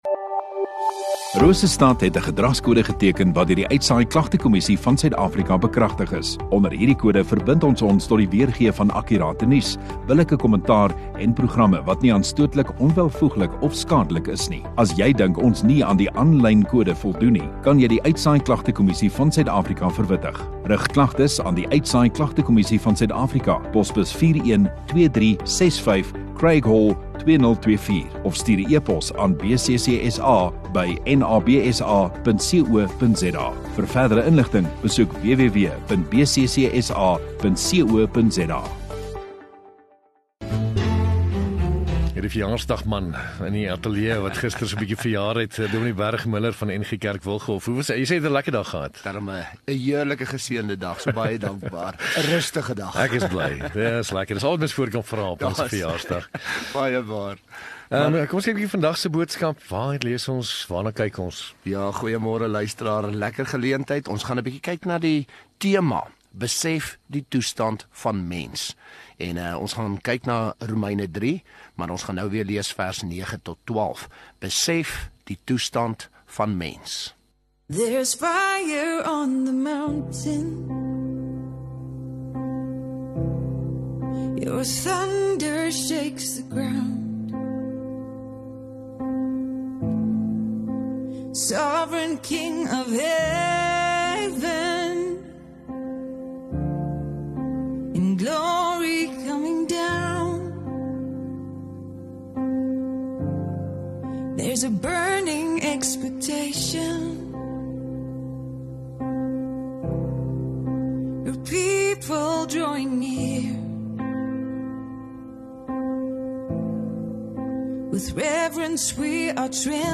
Rosestad Godsdiens 4 Jun Dinsdag Oggenddiens